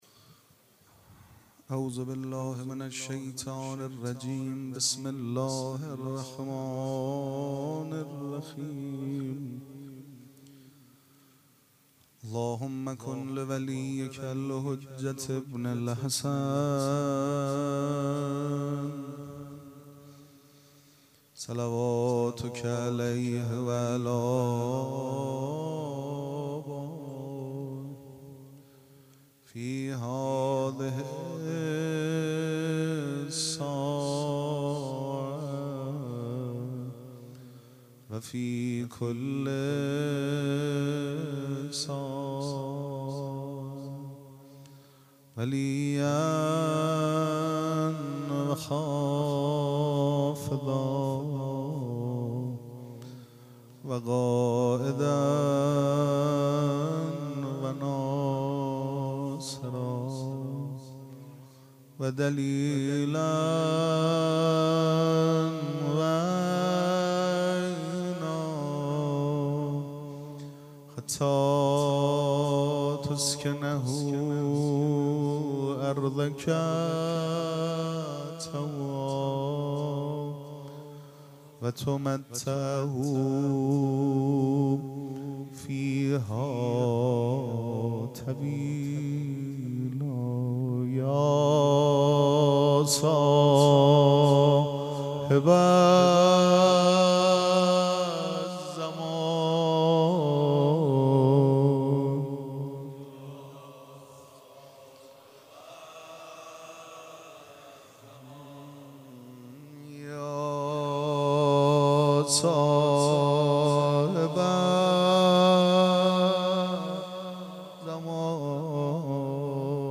مراسم عزاداری شب اول محرم الحرام ۱۴۴۷
سبک اثــر پیش منبر